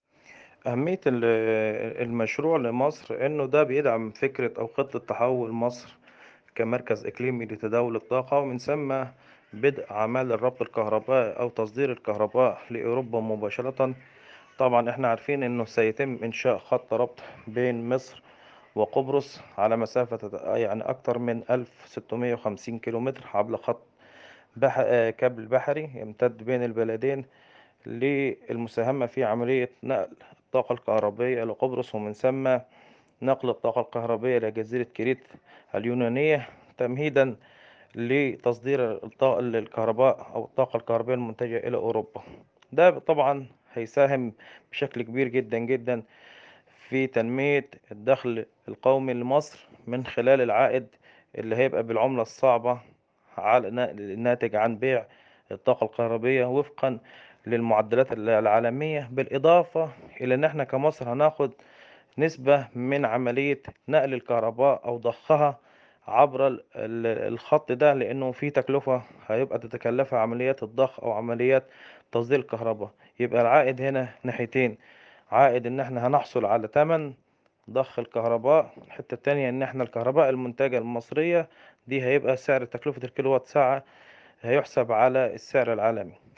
حوار
محلل اقتصادي ومتخصص في قطاع الكهرباء والطاقة